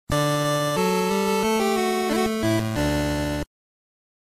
RICH FOLK DOORBELL 1